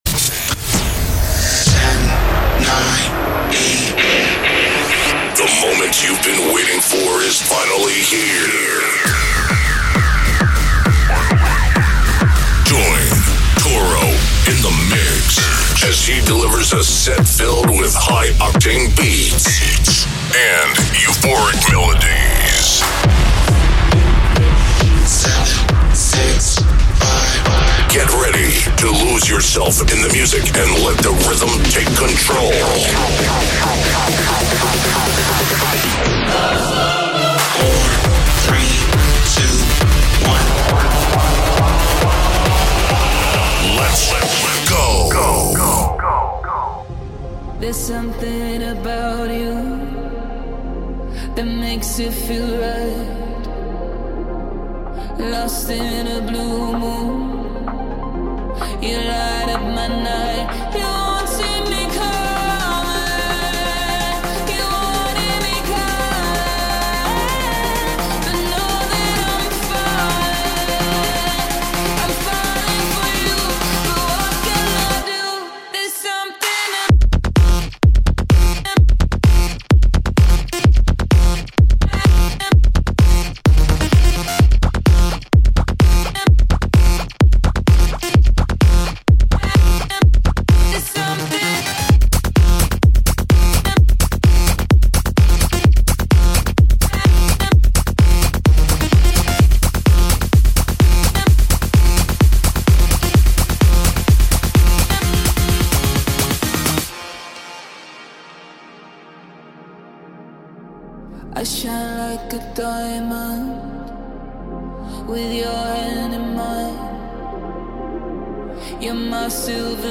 " the ultimate dance and trance music podcast.